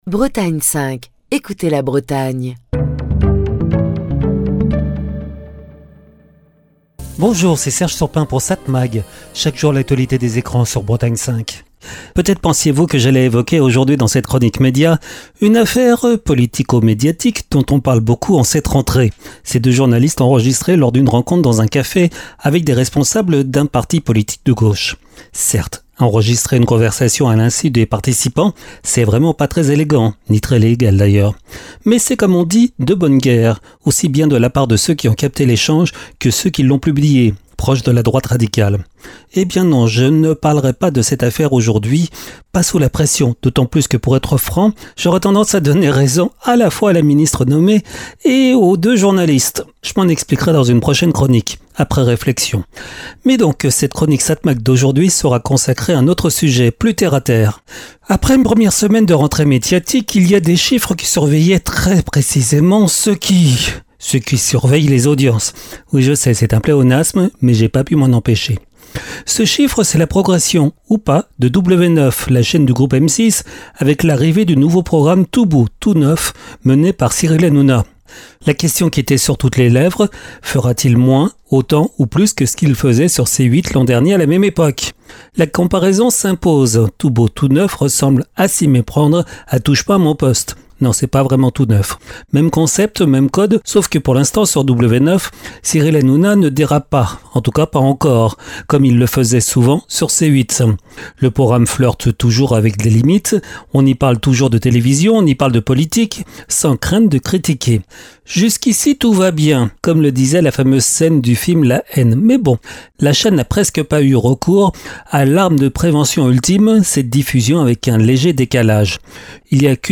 Chronique du 9 septembre 2025.